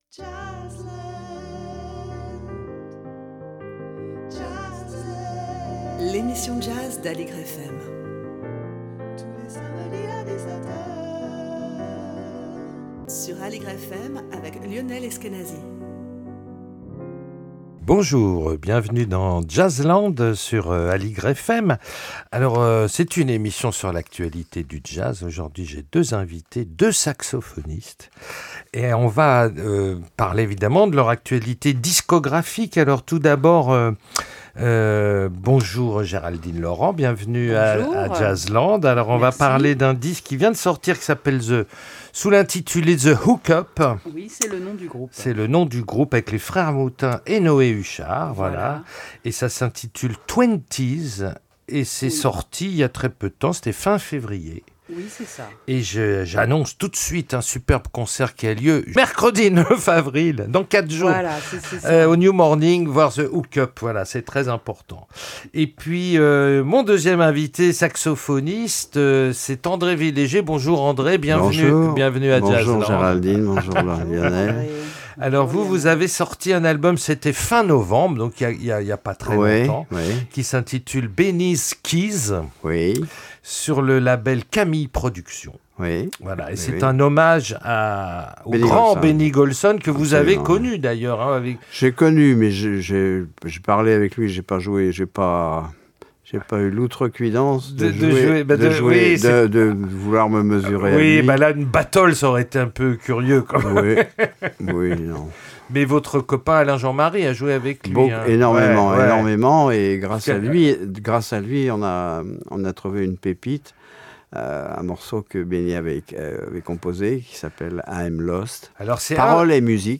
sur l'actualité du jazz avec deux invités